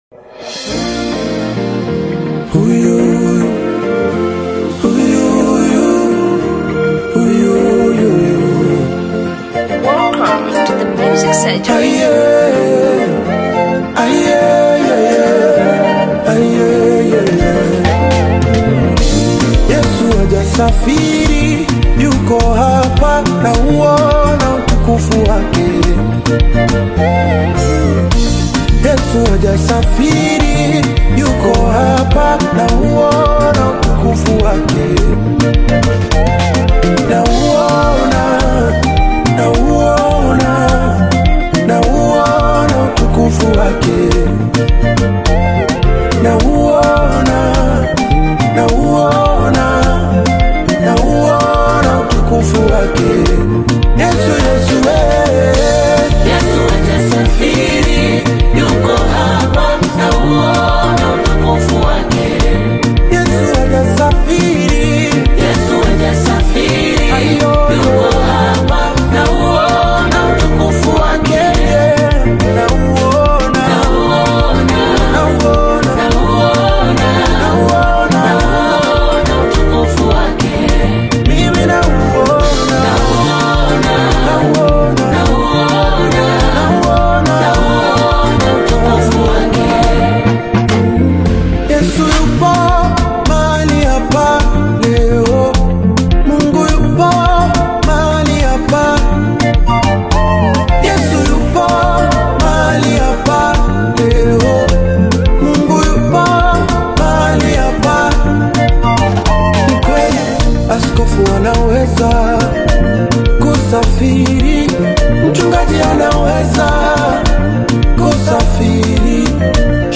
Tanzanian gospel music